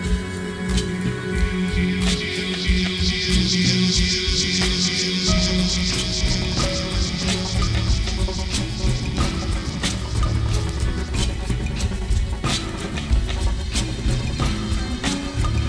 electronica, live! available media: mp3